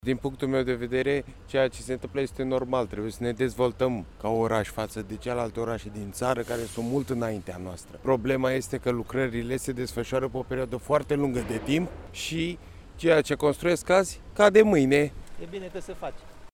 Șoferii intervievați